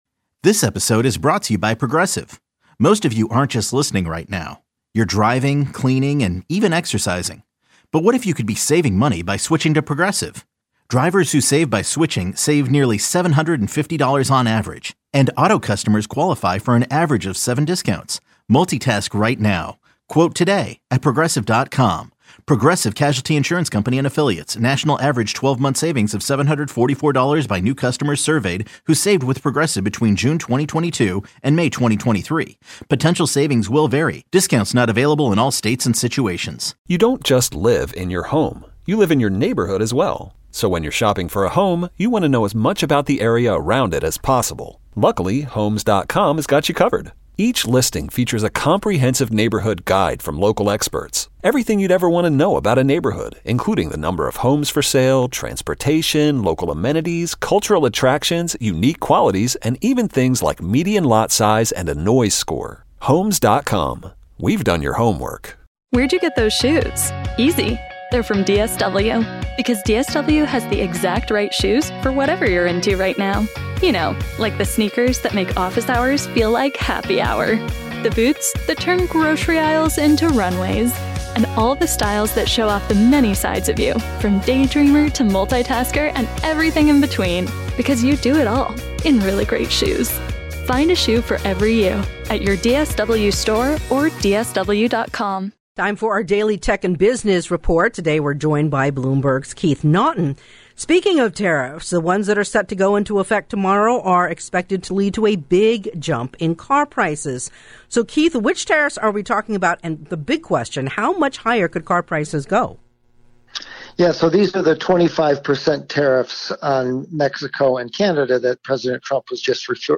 This is our daily Tech and Business Report.